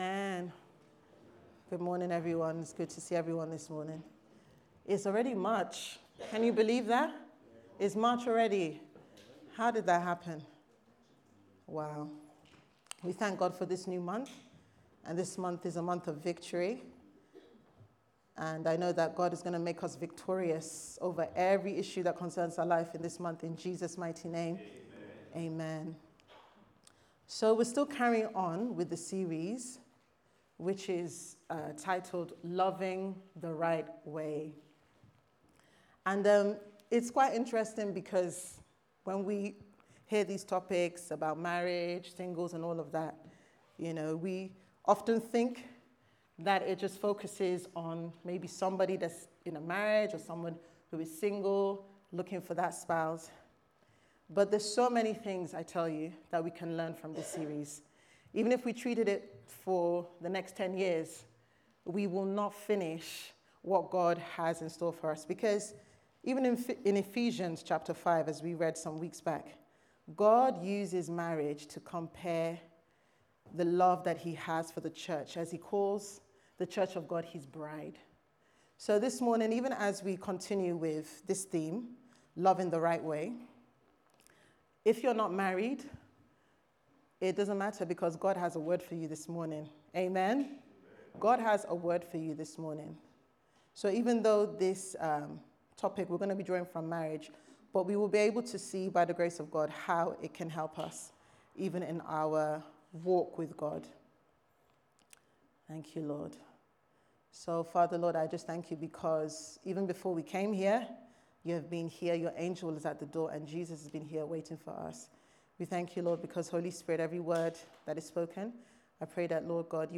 Sunday Service Sermon « Loving The Right Way